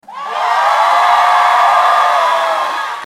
Crowd Cheering 02
Crowd_cheering_02.mp3